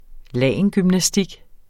Udtale [ ˈlæjˀəngymnaˌsdig ]